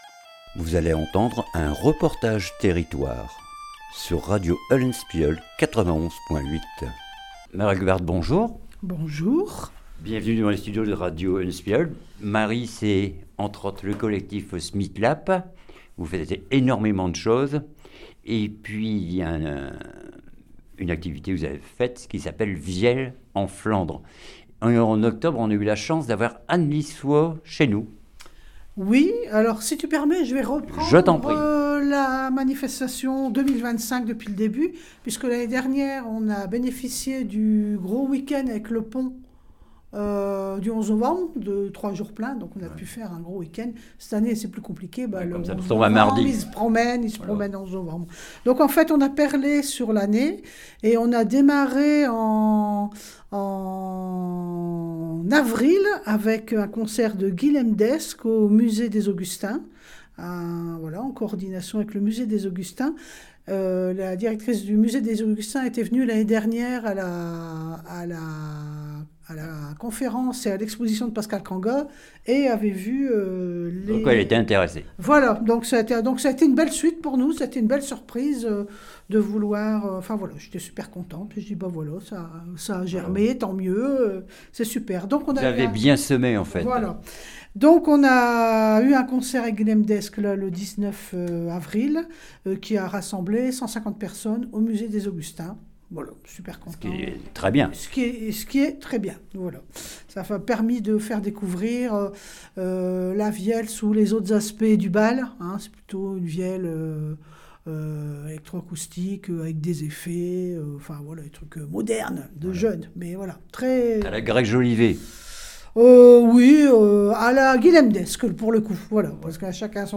REPORTAGE TERRITOIRE BAL FOLK 11 NOVEMBRE CASSEL SDF 15H